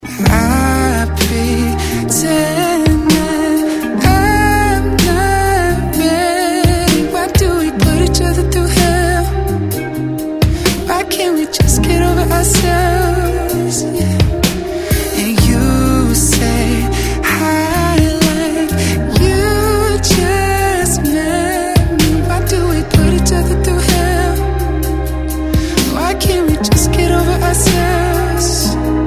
Категория: Спокойные рингтоны